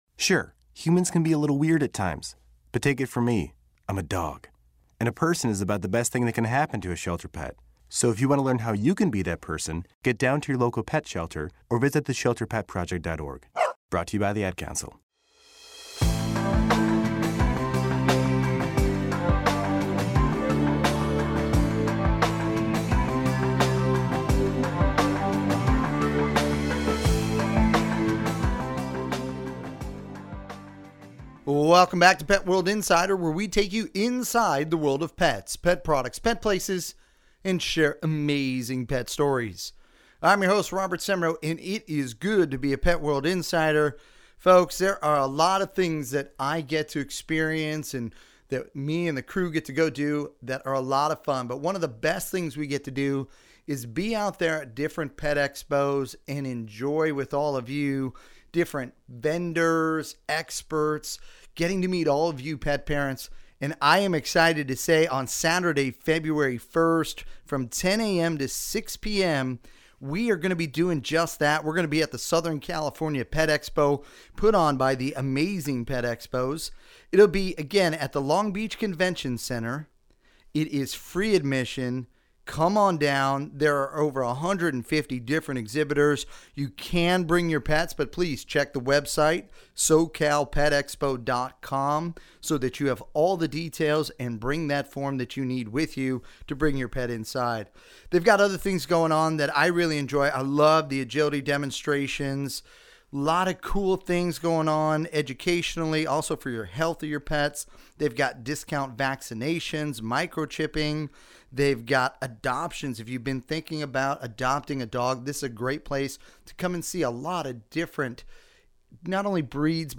Pet World Insider Radio Segment – Southern California Pet Expo – Saturday, February 1, 2014 10AM to 6PM